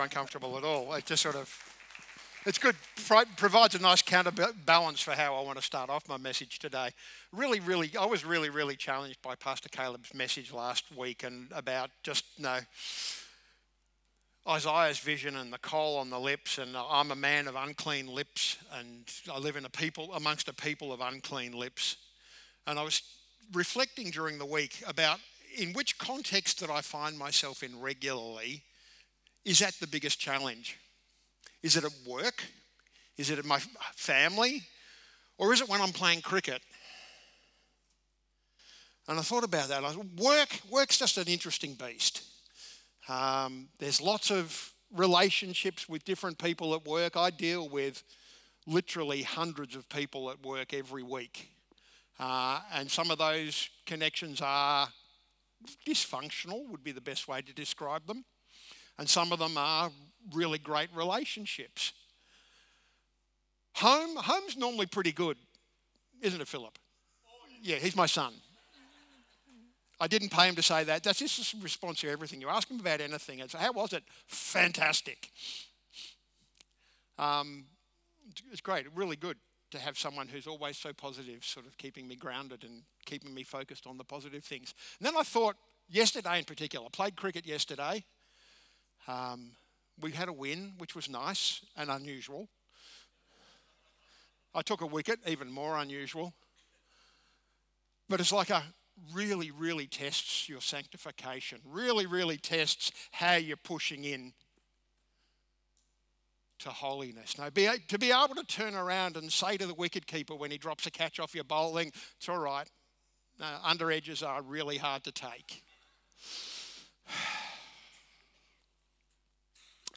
Sermons | Discover Church